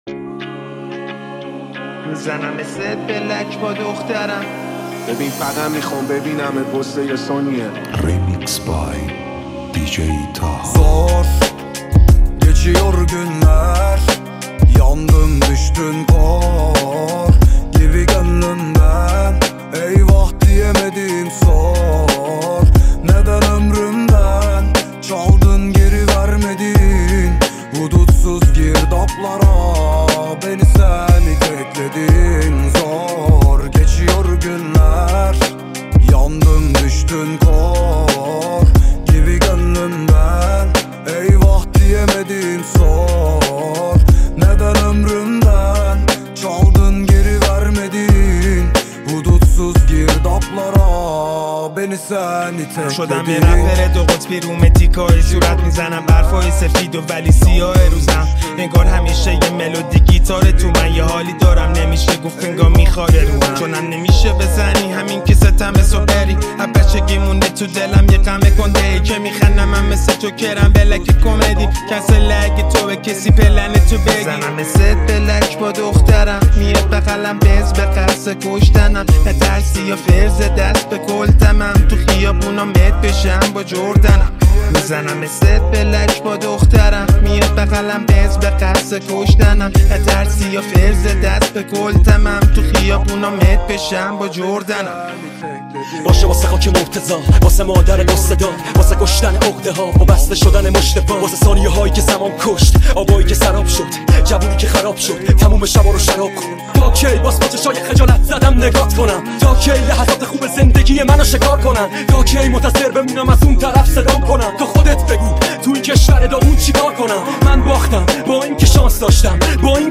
ریمیکس رپی
ریمیکس جدید رپ